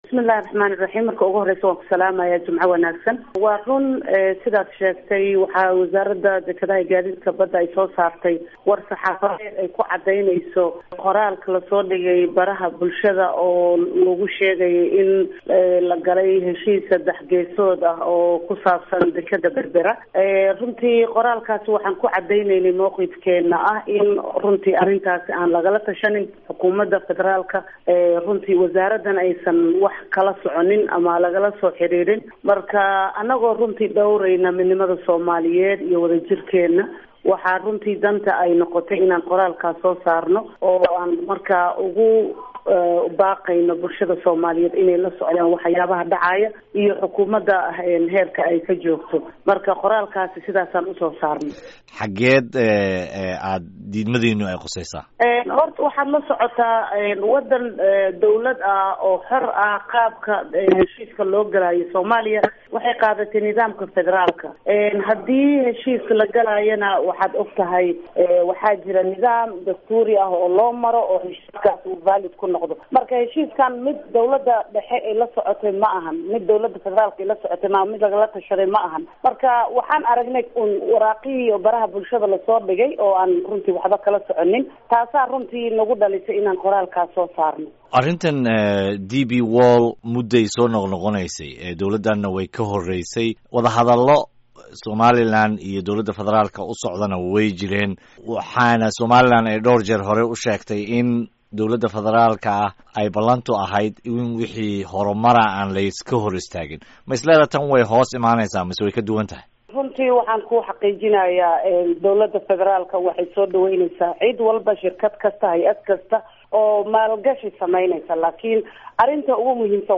Wareysiga Wasiirka Dekedaha iyo Gaadiidka Badda